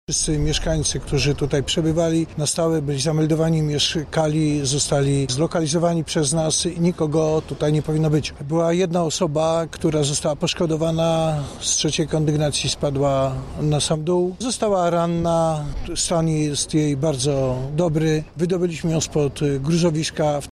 Szczegóły tłumaczy generał brygadier Leszek Suski, Komendant Główny Państwowej Straży Pożarnej: